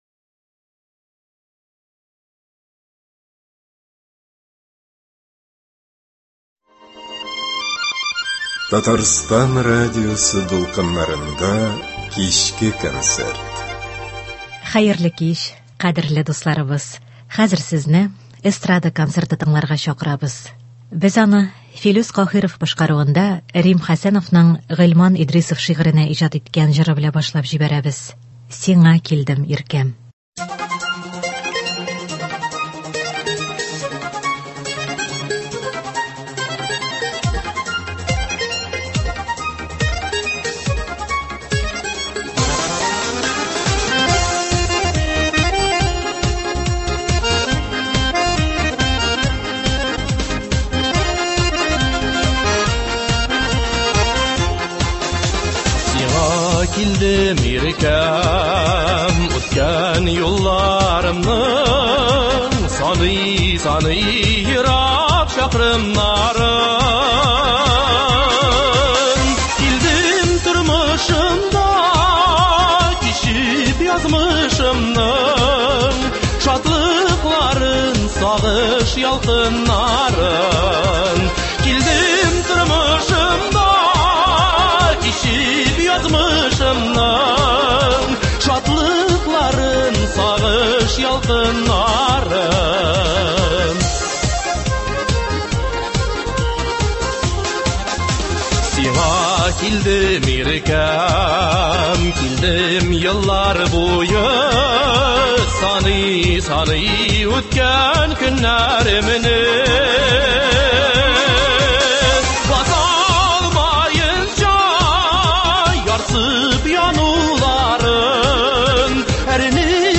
Көндезге концерт. Кичке концерт. Эстрада концерты.